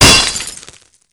wrench1.wav